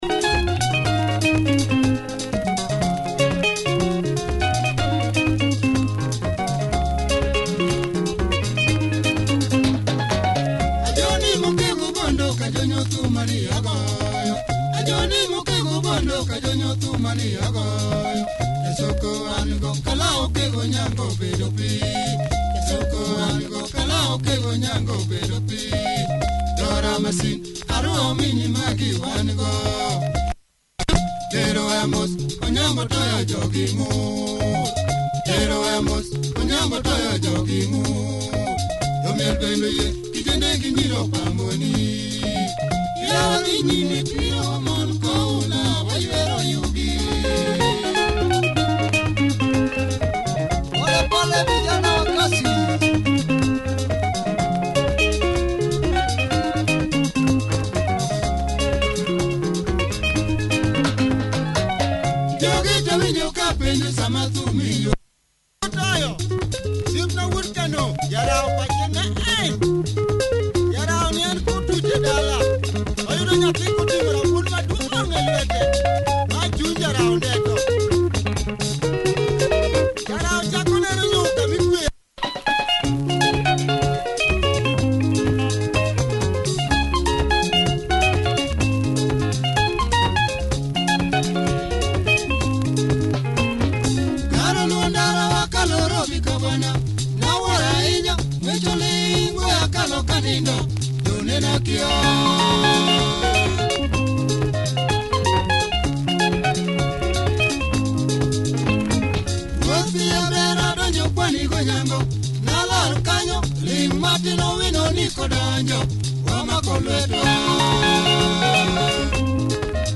Nice drive in this LUO benga number, cool guitar hook!